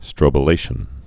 (strōbə-lāshən)